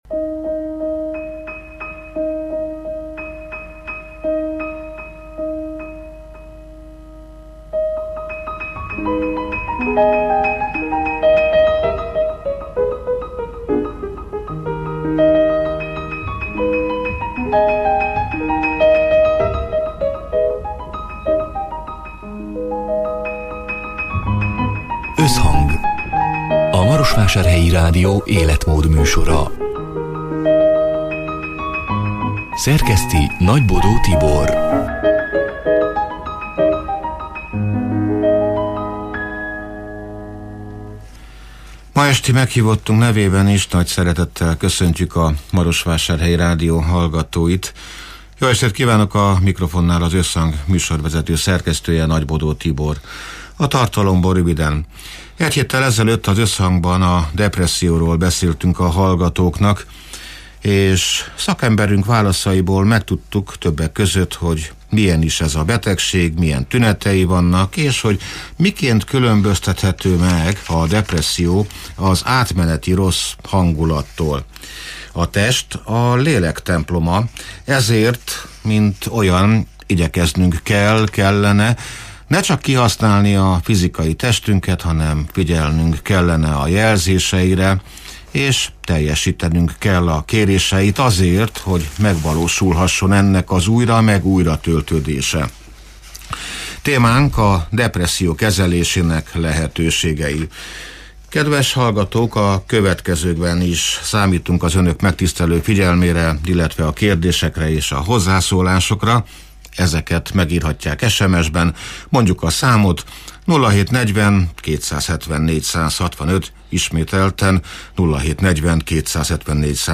(elhangzott: 2025. február 19-én, szerdán délután hat órától élőben)